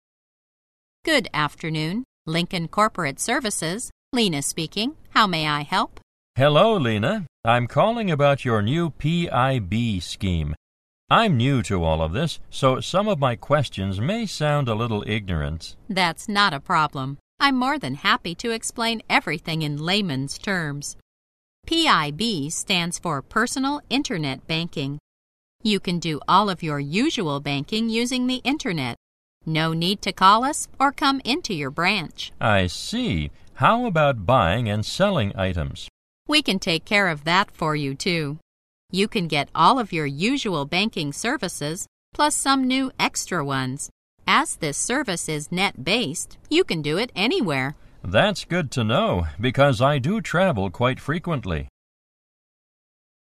在线英语听力室银行英语情景口语 第112期:电子银行 网上银行业务(4)的听力文件下载, 《银行英语情景口语对话》,主要内容有银行英语情景口语对话、银行英语口语、银行英语词汇等内容。